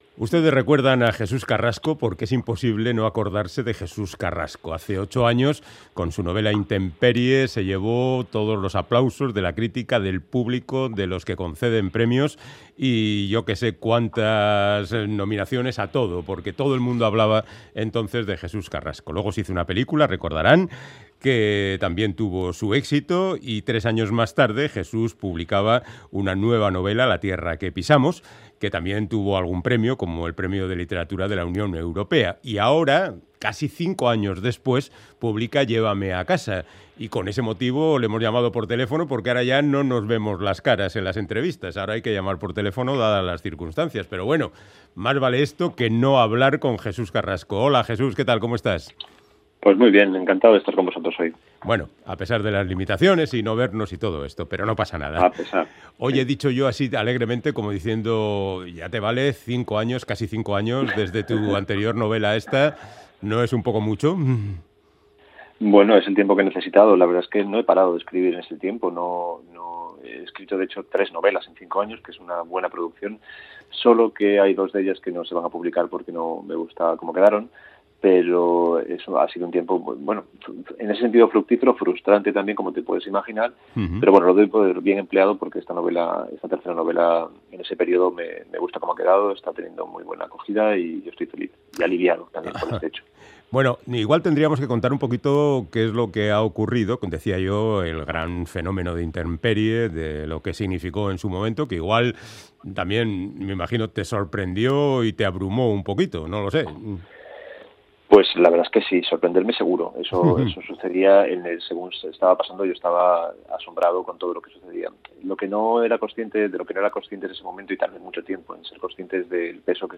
Audio: Charlamos con Jesús Carrasco, autor de "Intemperie", que vuelve con otra novela titulada "Llévame a casa", que habla del pasado y el lugar en el mundo